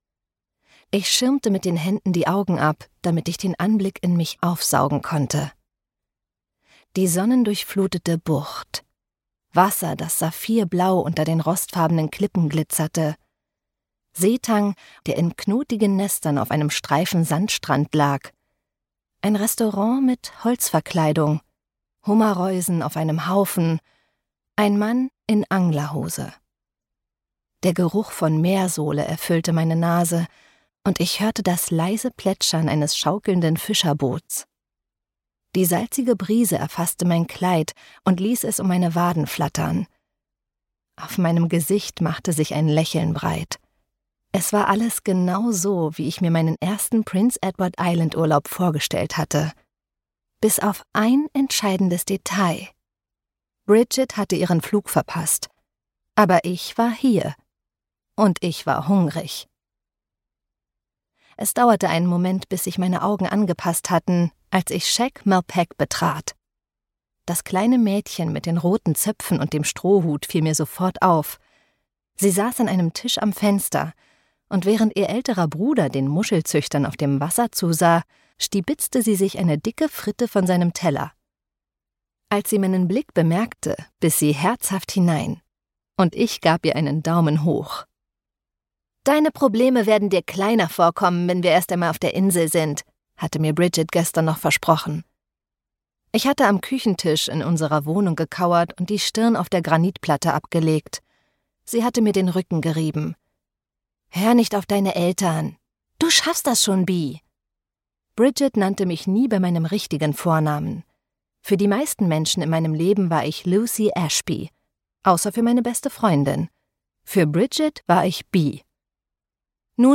Dieser Sommer wird anders (DE) audiokniha
Ukázka z knihy